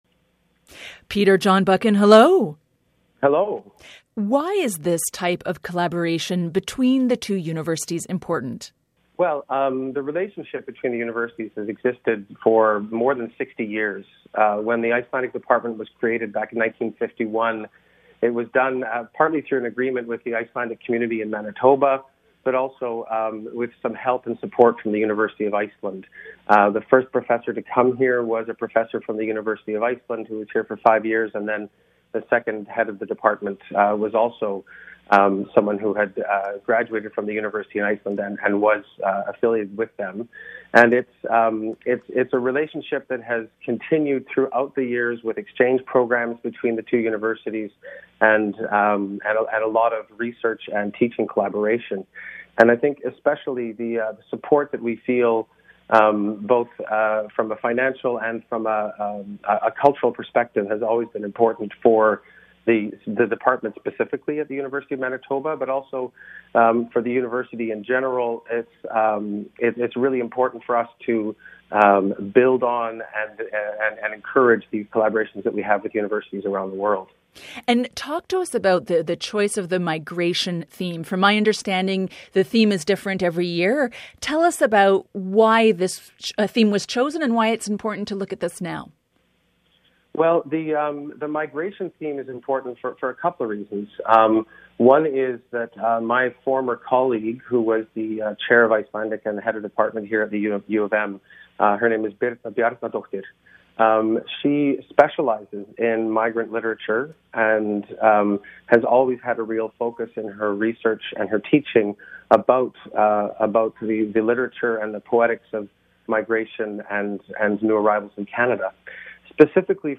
Feature Interview: